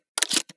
toz194_shell.ogg